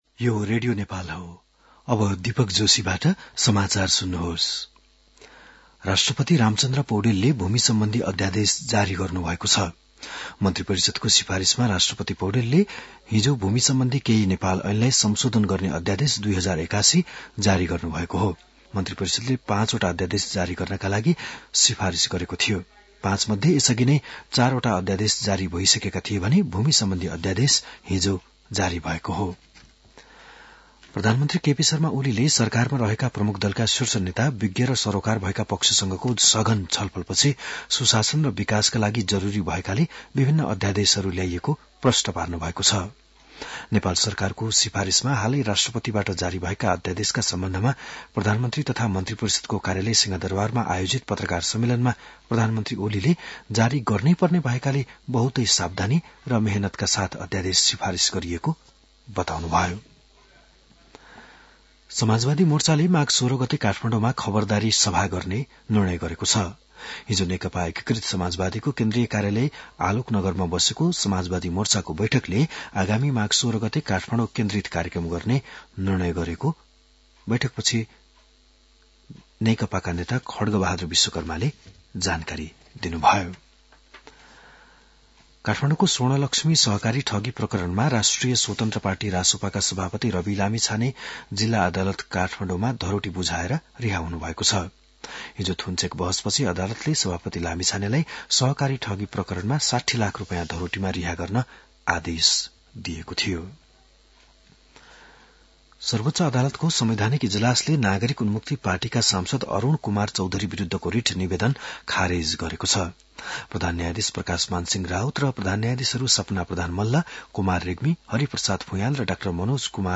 बिहान ११ बजेको नेपाली समाचार : ४ माघ , २०८१
11-am-news-1-7.mp3